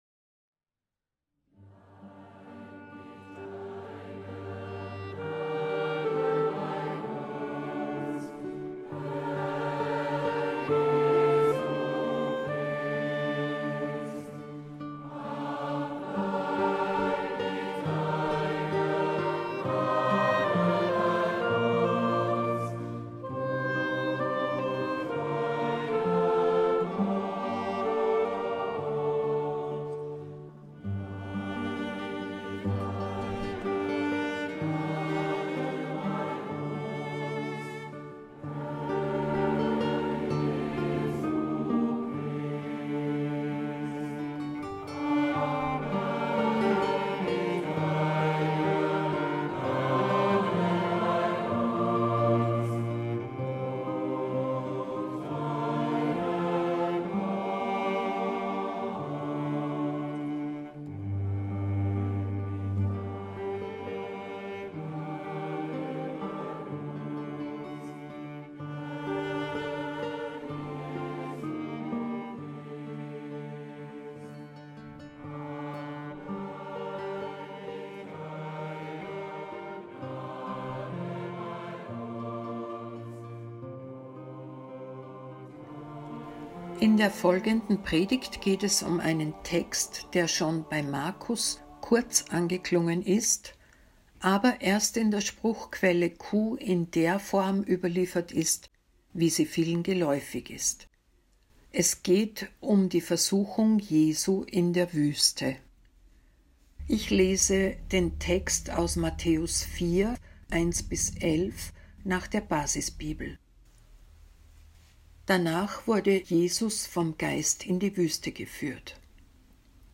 Predigt | NT01 Matthäus 4,1-11 Die Versuchung Jesu in der Wüste – Glauben und Leben